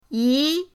yi2.mp3